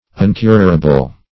uncurable - definition of uncurable - synonyms, pronunciation, spelling from Free Dictionary Search Result for " uncurable" : The Collaborative International Dictionary of English v.0.48: Uncurable \Un*cur"a*ble\, a. Incurable.